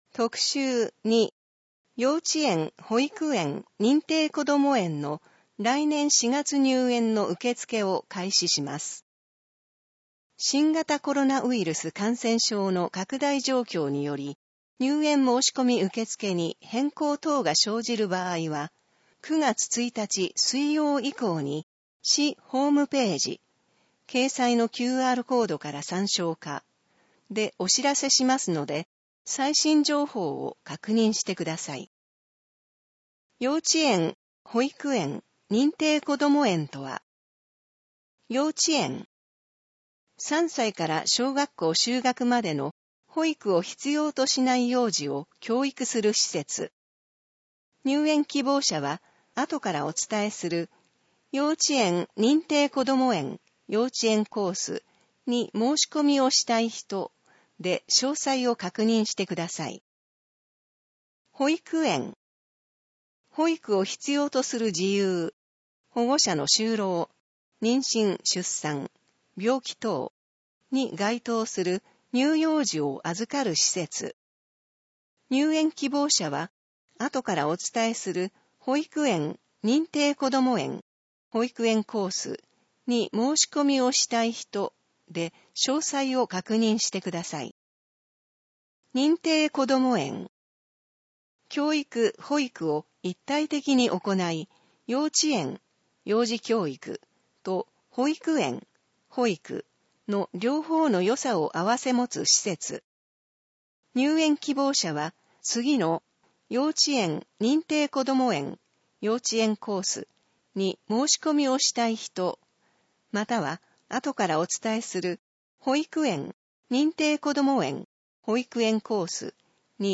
なお、以上の音声データは、「音訳ボランティア安城ひびきの会」の協力で作成しています。